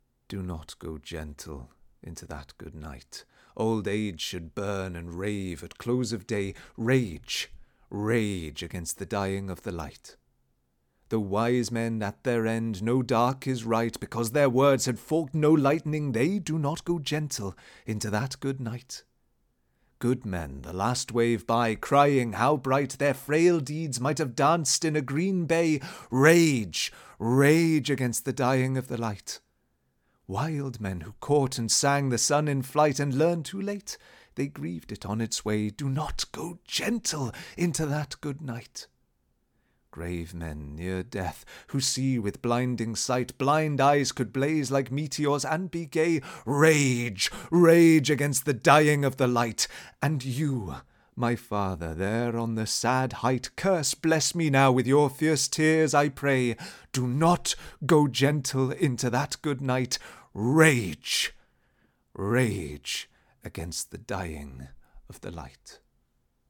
Welsh accent (South)
Welsh-South-Accent-Sample.mp3